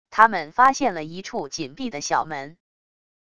他们发现了一处紧闭的小门wav音频生成系统WAV Audio Player